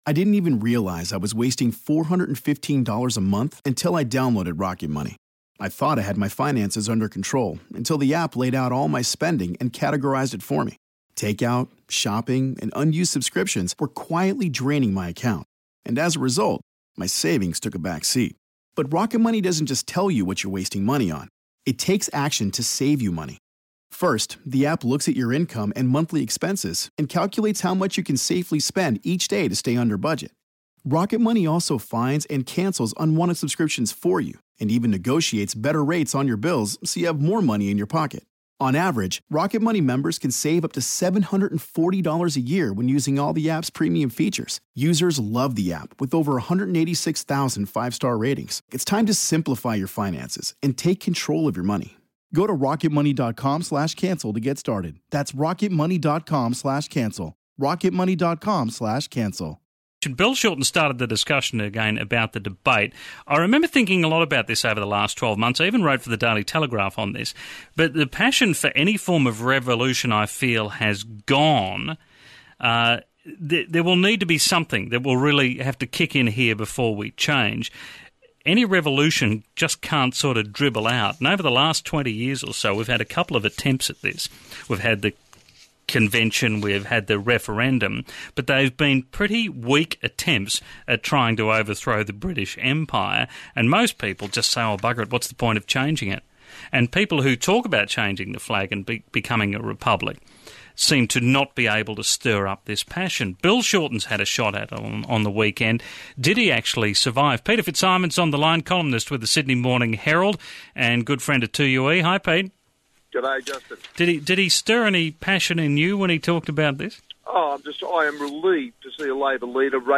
speaks with campaigner Peter FitzSimons on our head of state.